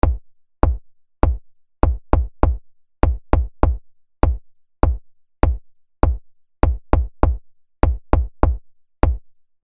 … kann beispielsweise so etwas werden …